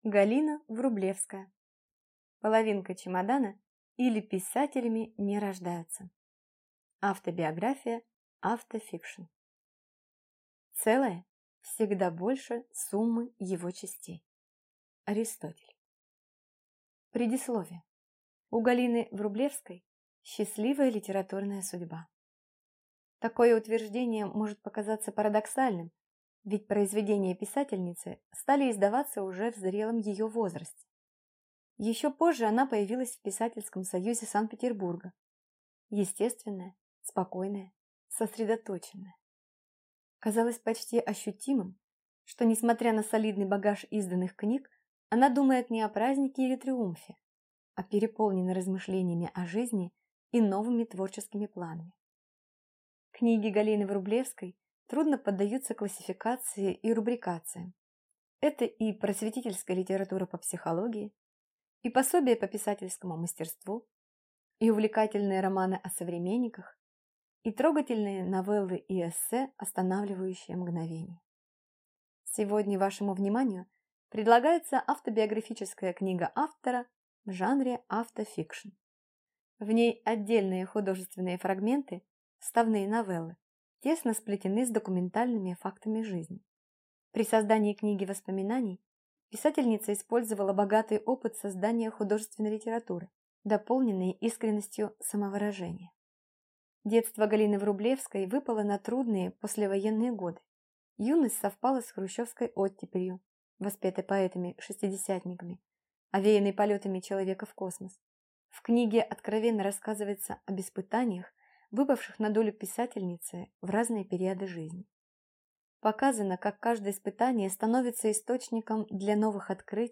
Аудиокнига Половинка чемодана, или Писателями не рождаются | Библиотека аудиокниг